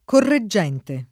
correggente [ korre JJ$ nte ]